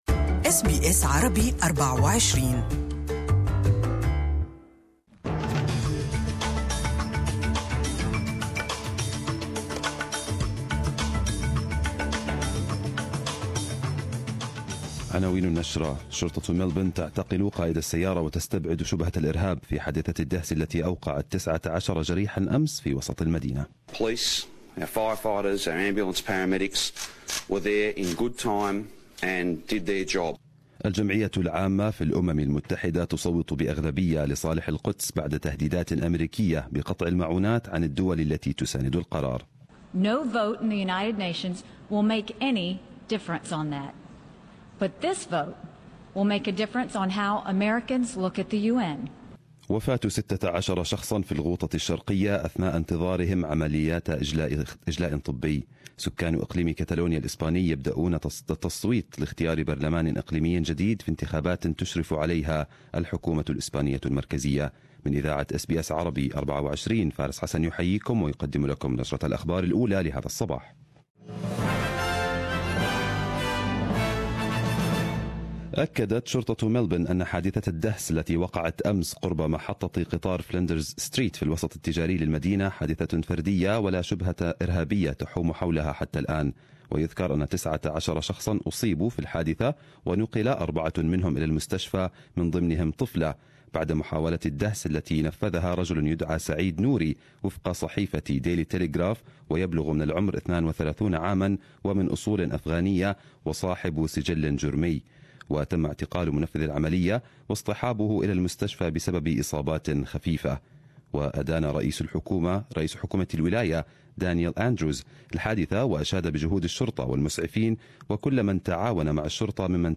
Arabic News Bulletin 22/12/2017